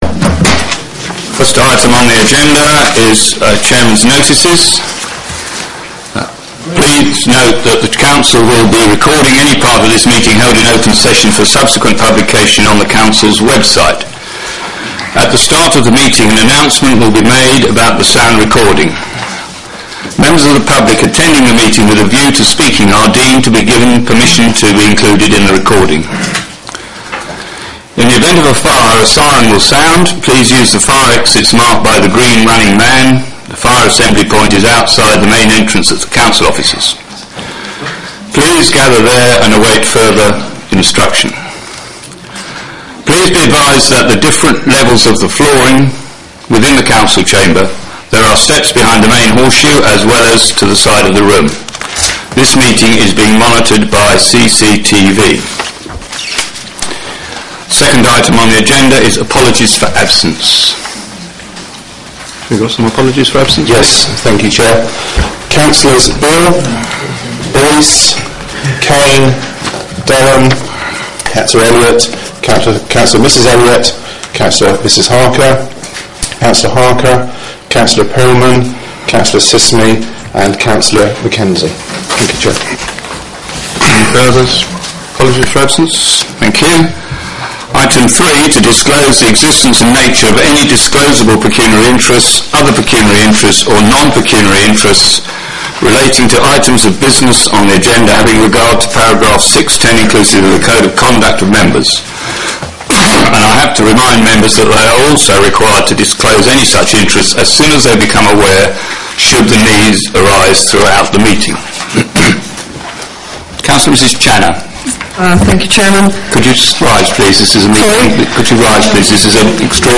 Council (Extraordinary - Planning) meeting audio recordings | Maldon District Council